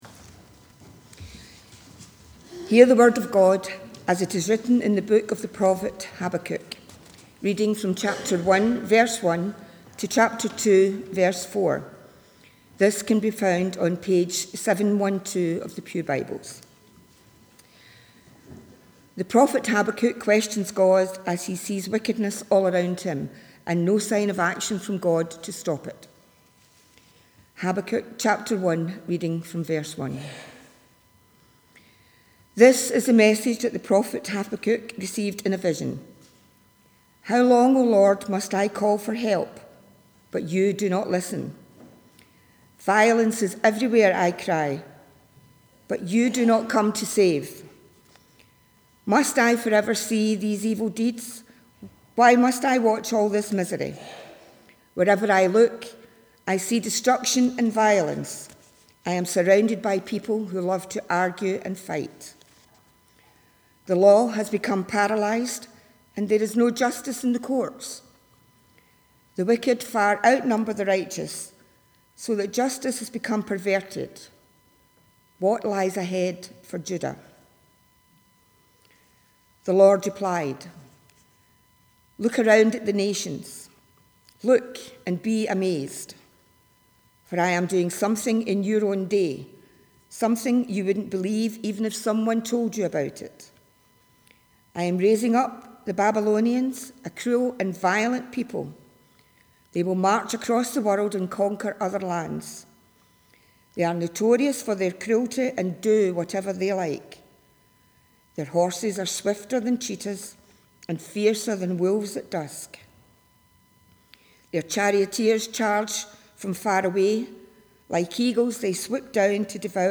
The reading prior to the sermon is Habakkuk 1:1 – 2:4